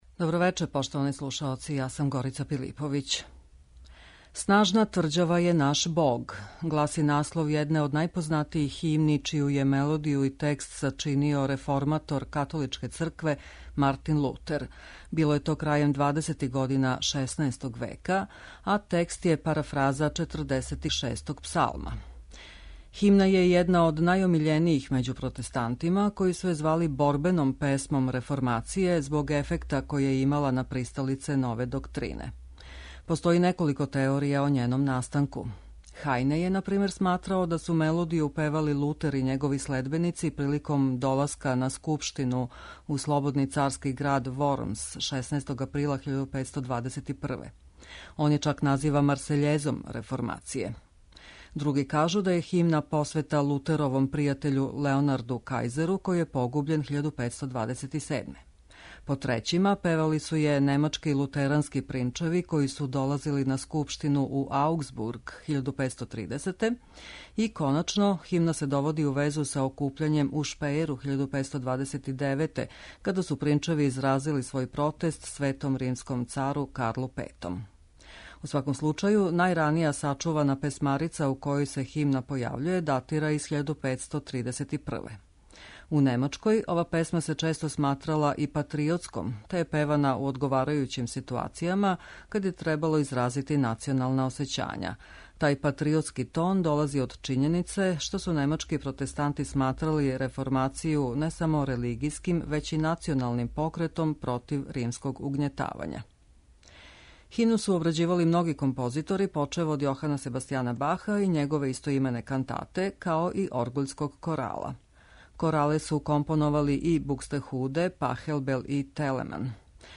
можете слушати коралну фантазију на ту тему Макса Регера.
у ВИСИНАМА представљамо медитативне и духовне композиције аутора свих конфесија и епоха.